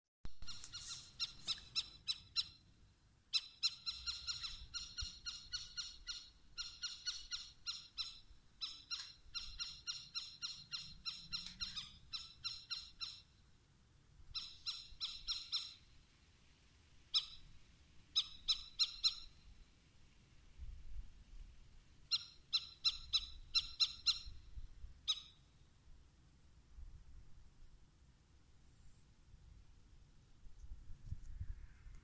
Suena como un chimpancé pequeño, si alguien me sabe decir cómo puedo subir aquí el audio os lo podría mostrar ;) Saludos!
Por la descripción y el audio yo diría mirlo cabreado.
Eso es un mirlo cabreado o asustado.
Los audios que se suelen encontrar por ahí suelen ser de cantos, que en el caso del mirlo es variado y melodioso, pero no suelen encontrarse los reclamos de alarma que son mucho más estridentes, idénticos al que nos has enviado.
pajaro_mono_nocturno.mp3